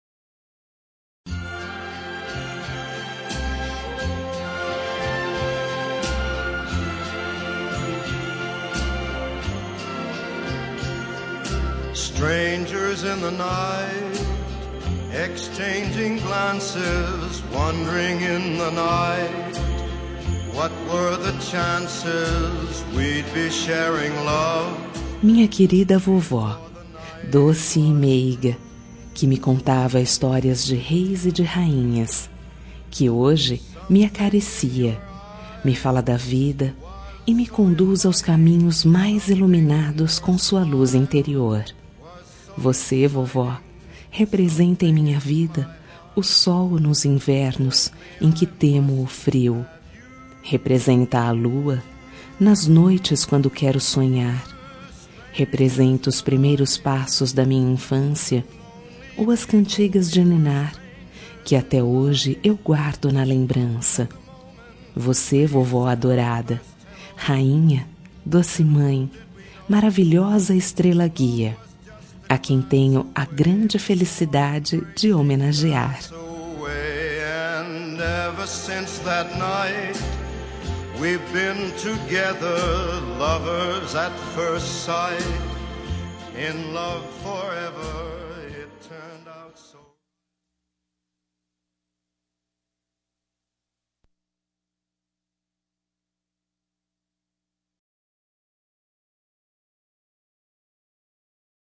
Homenagem para Avó – Voz Feminina – Cód: 303